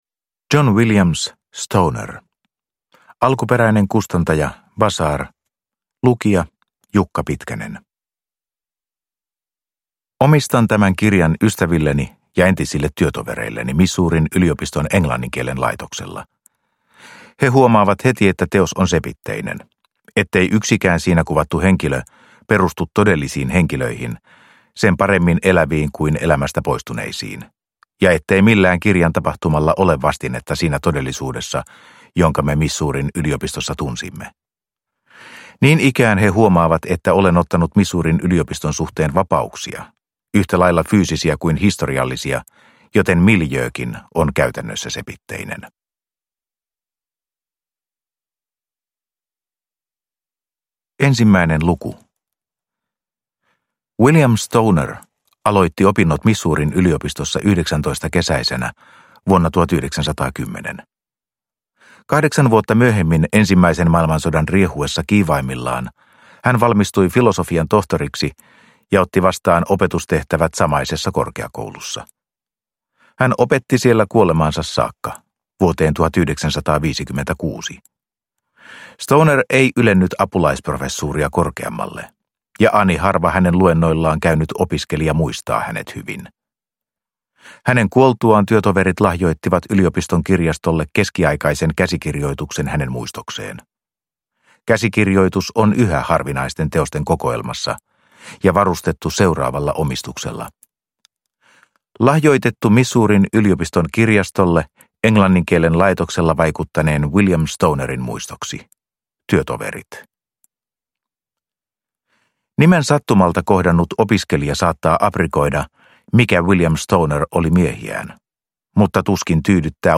Stoner – Ljudbok – Laddas ner